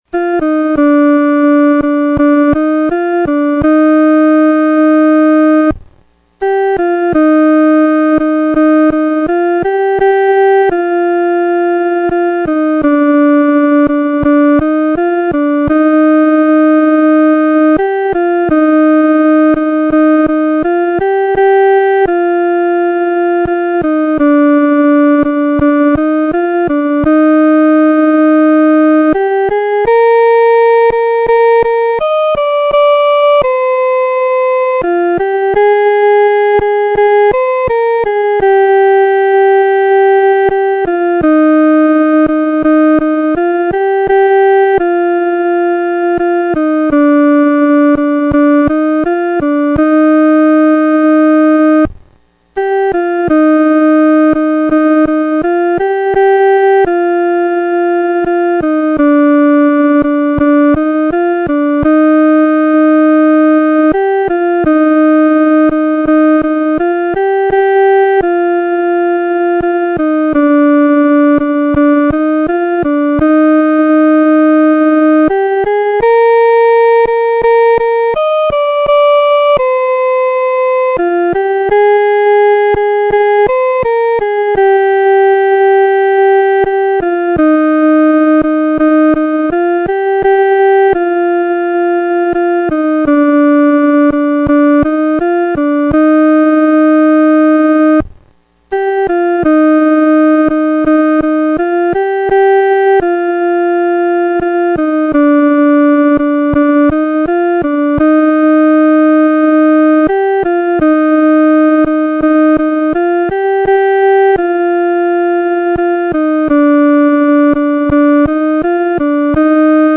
独奏（第一声）